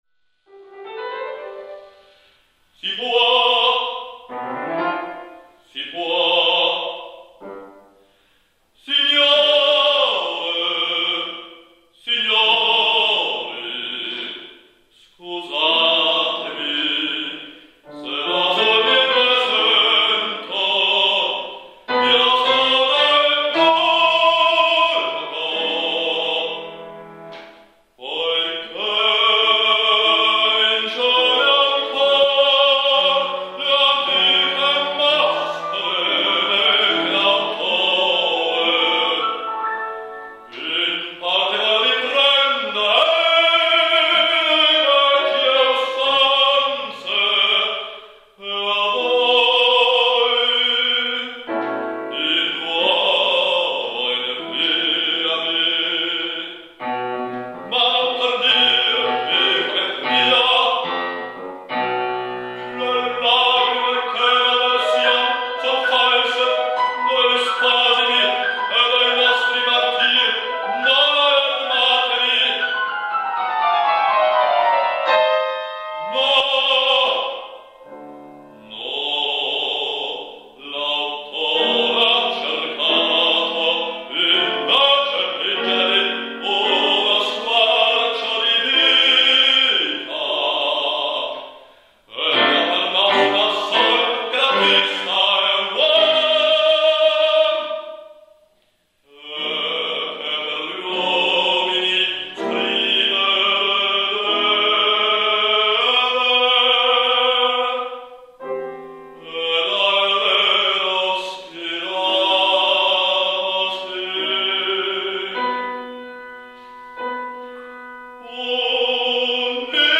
Bassbariton